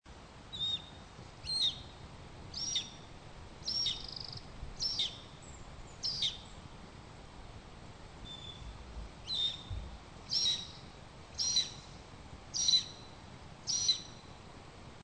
Aguilucho Común Aguilucho Alas Largas
Buteo polysoma Buteo albicaudatus